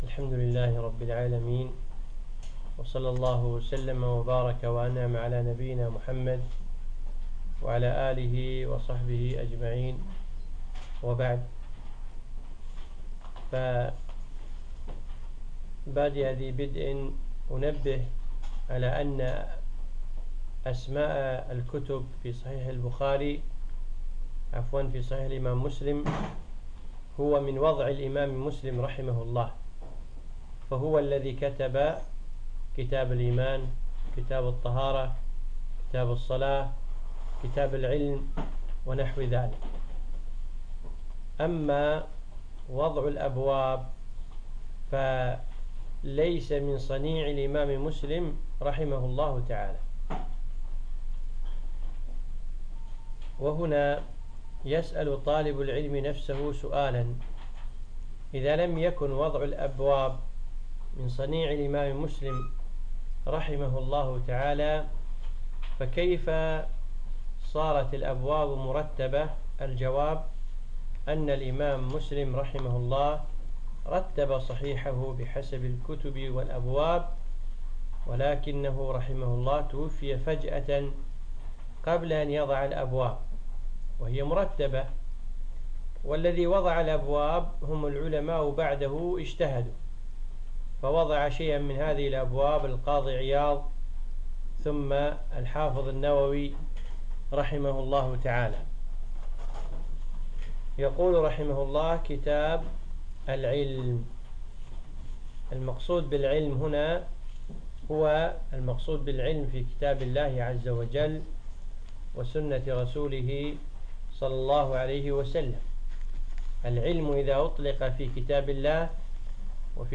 تم إلقاء هذا الدرس يوم الأربعاء 8 / 4 / 2015 في مركز دار القرآن نساء مسائي في منطقة القصر